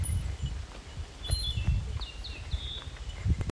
センダイムシクイの囀りを取り入れたキビタキ 2025,7.13奥日光で録音。１０分以上同じフレーズを繰り返していました。